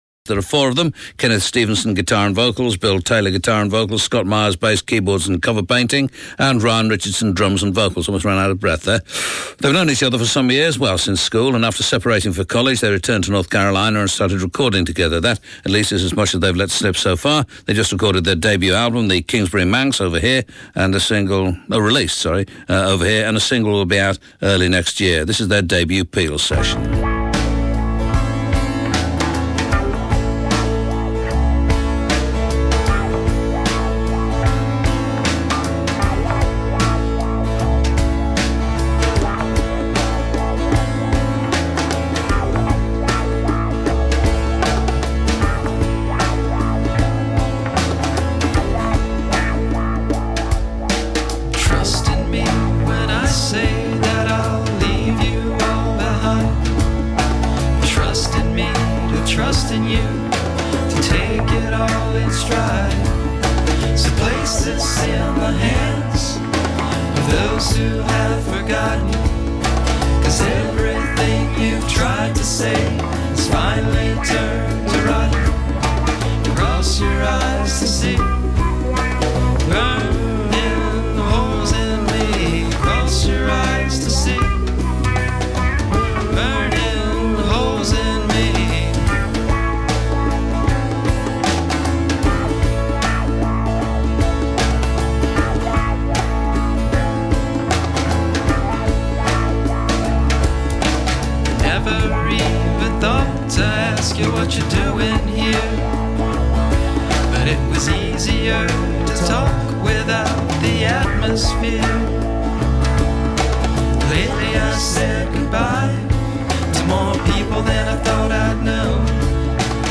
American Indie
in session tonight
Indie from Chapel Hill.
guitar/vocals
drums/vocals
bass/keyboards